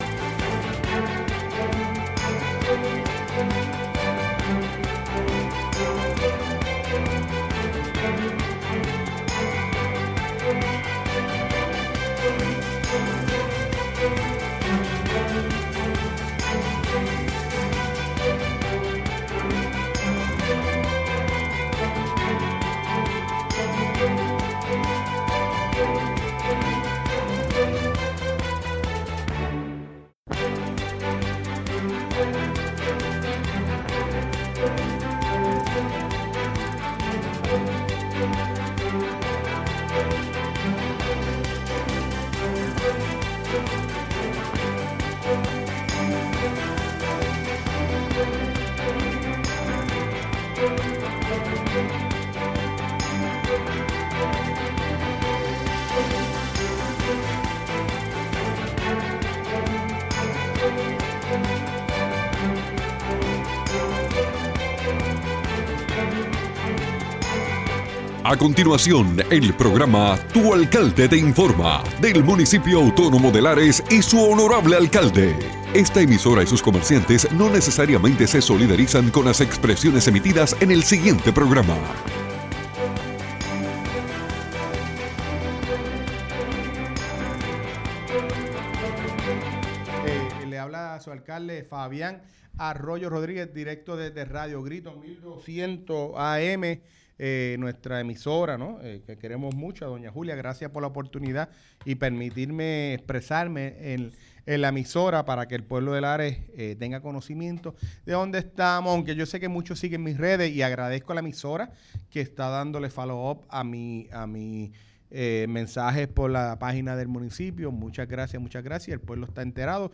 Hoy el Alcalde Fabian Arroyo nos habla sobre la situación del agua y luz en Lares.